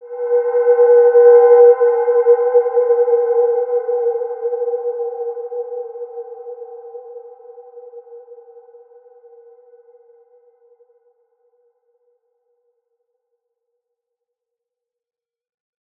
Wide-Dimension-B3-mf.wav